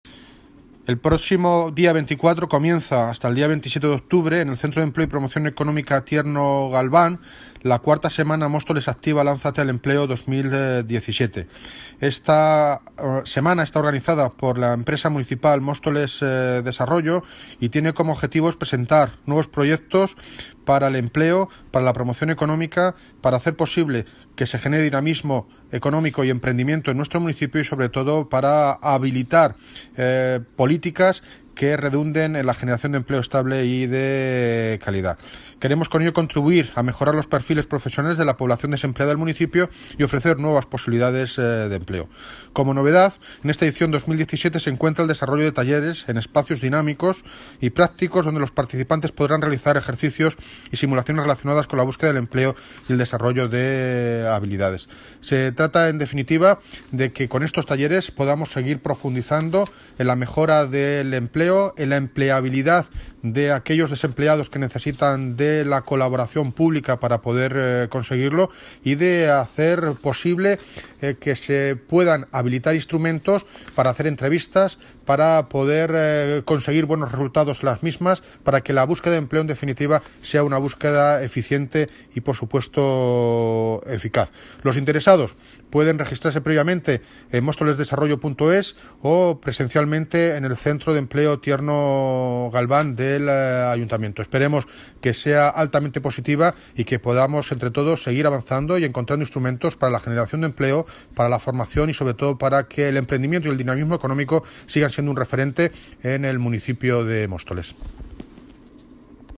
El Alcalde anuncia las novedades de la IV Semana Móstoles Activa `Lánzate al Empleo 2017´
Audio - David Lucas (Alcalde de Móstoles) Sobre Móstoles Activa